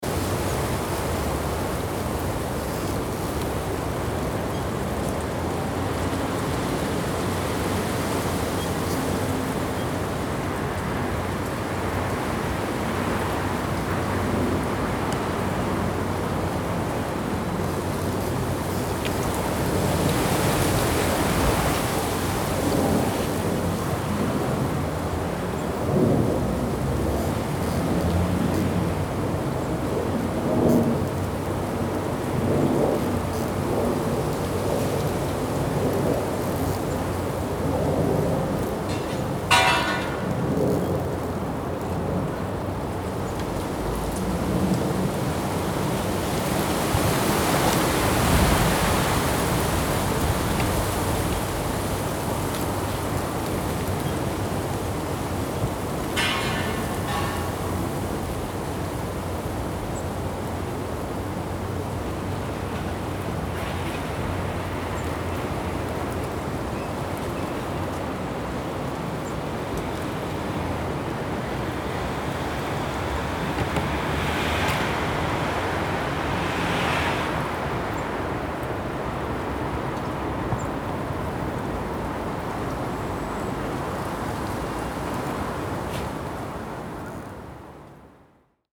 02zonweg2leavesedit.mp3